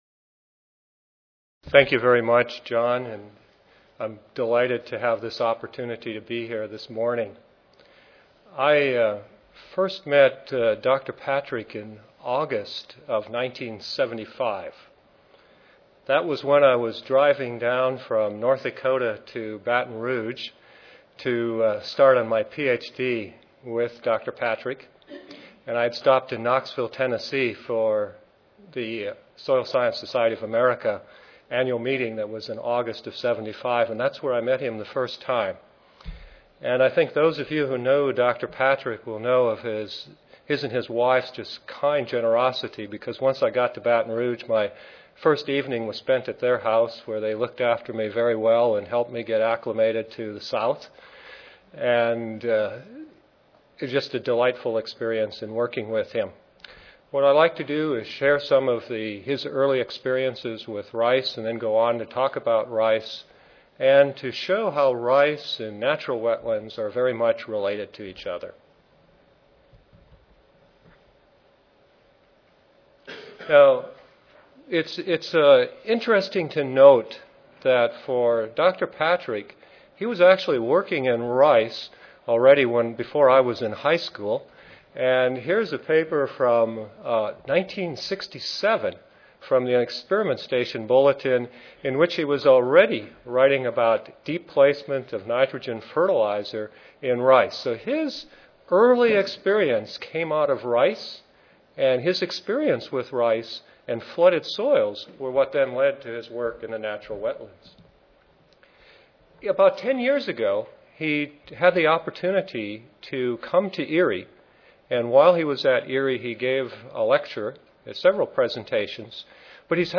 International Rice Research Institute Recorded Presentation Audio File